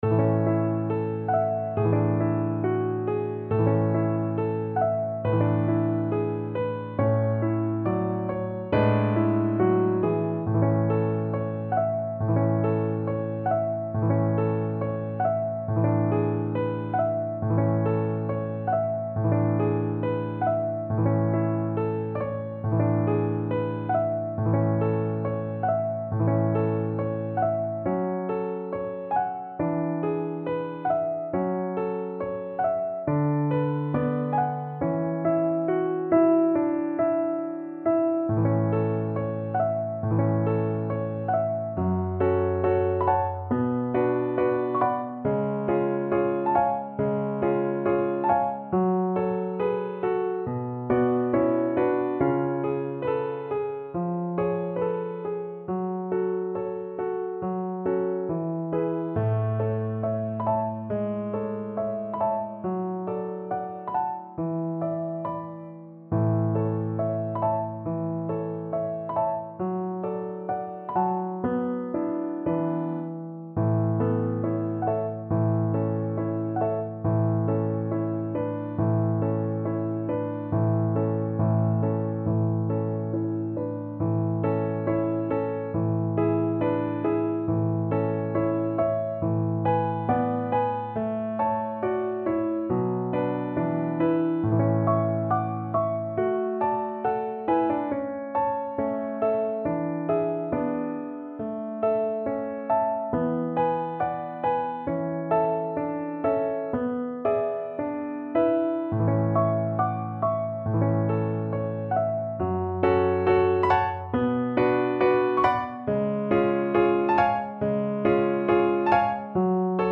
~ = 69 Andante tranquillo